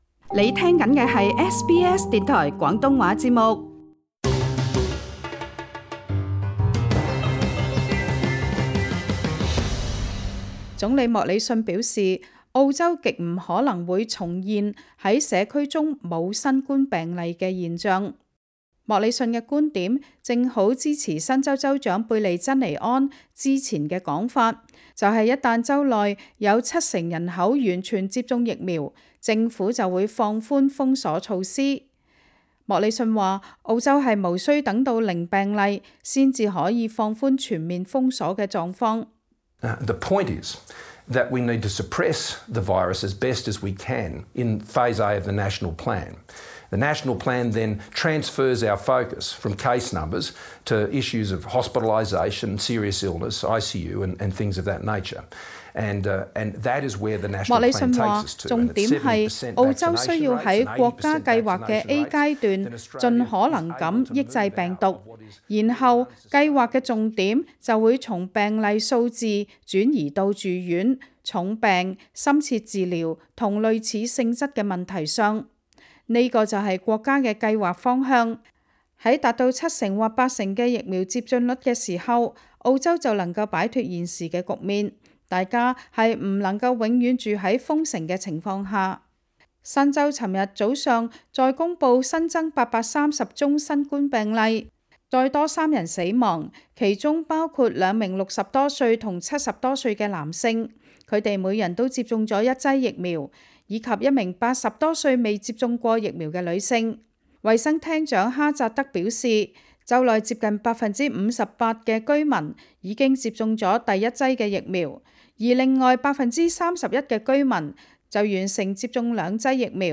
Source: AAP SBS廣東話節目 View Podcast Series Follow and Subscribe Apple Podcasts YouTube Spotify Download (7.78MB) Download the SBS Audio app Available on iOS and Android 澳洲總理莫理遜表示，澳洲極不可能會重現在社區中沒有新冠病例的狀況。